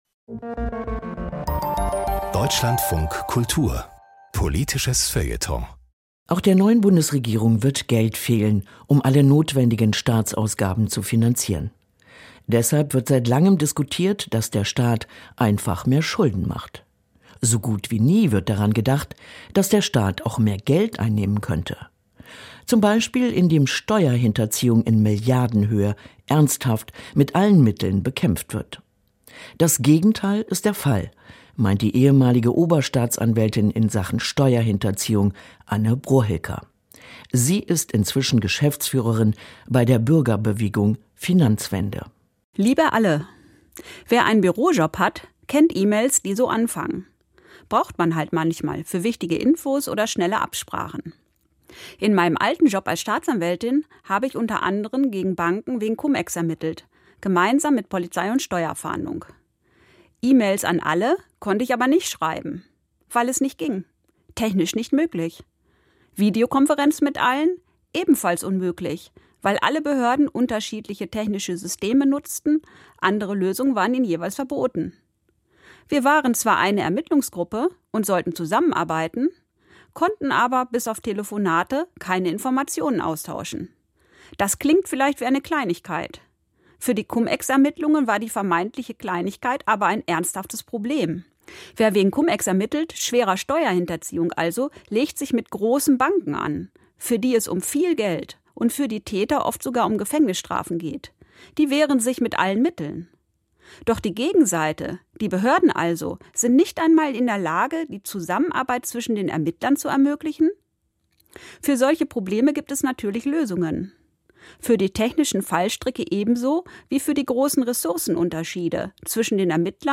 Meinung - Professionellere Steuerfahndung könnte Milliarden einbringen